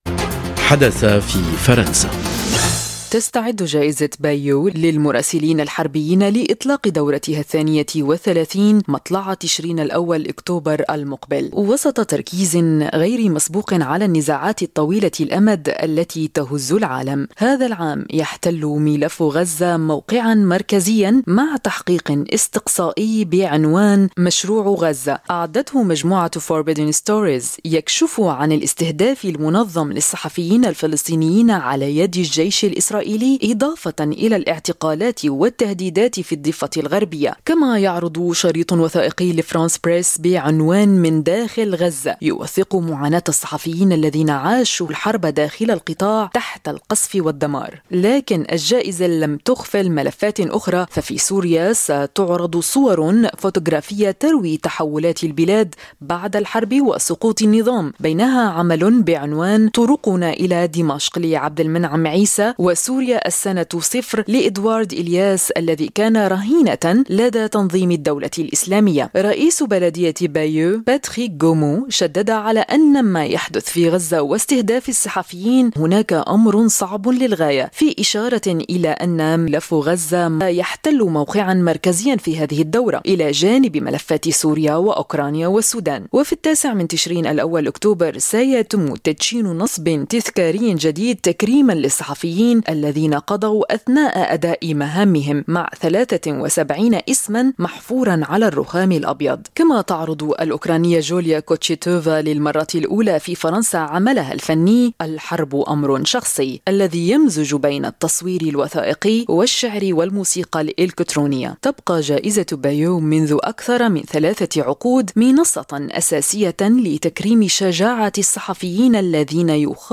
حدث في فرنسا : تقرير: من غزة إلى سوريا وأوكرانيا، جائزة بايو تكرّم الحقيقة وسط الحروب